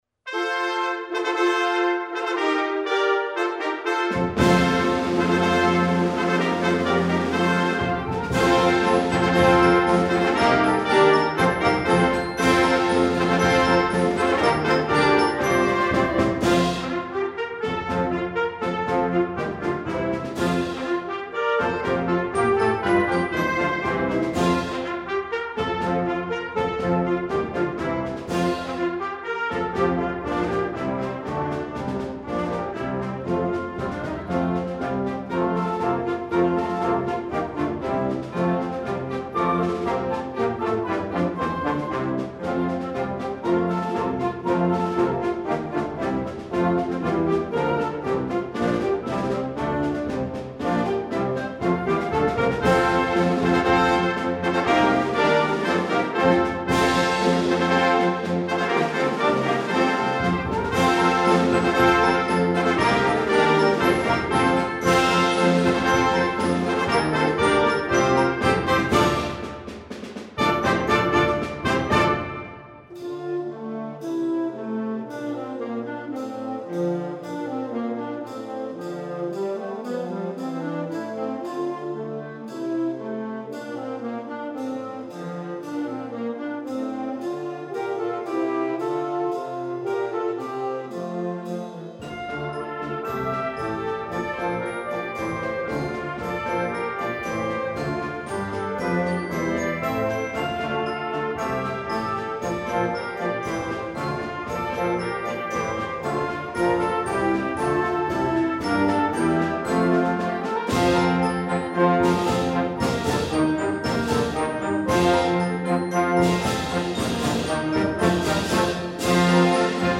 試聴サンプル